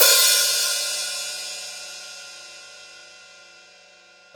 • Big Ride D# Key 01.wav
Royality free ride cymbal drum sample tuned to the D# note. Loudest frequency: 6782Hz
big-ride-d-sharp-key-01-7aS.wav